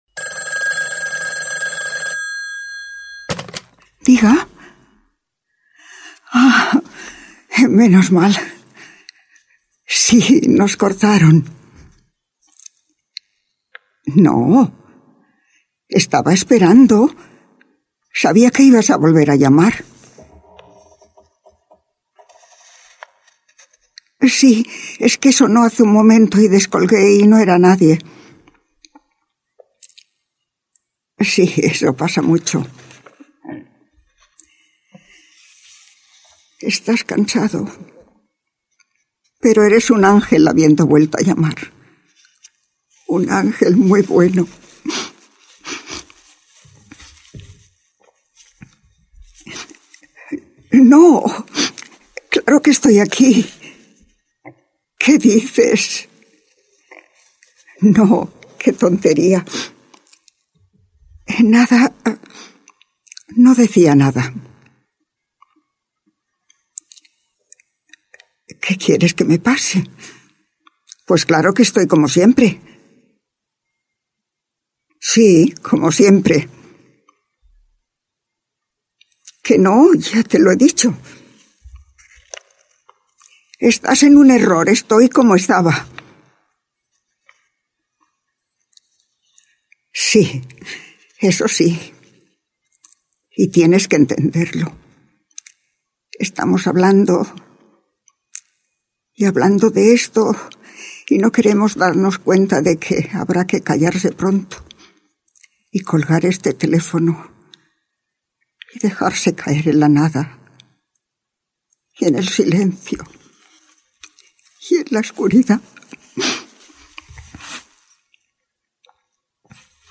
Radioteatre: La voz humana (interpreta a Ella) - Ràdio Terrassa, 2017